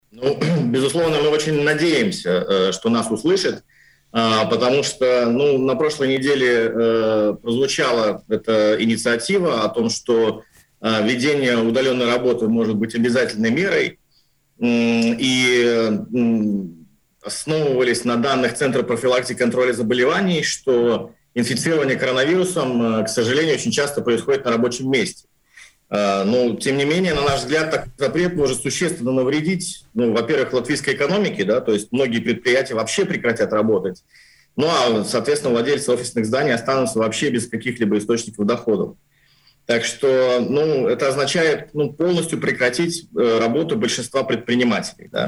В эфире радио Baltkom сегодня обсуждали темы: пустые офисы из-за «удаленки» , «зеленая» Латвия, травля в школе.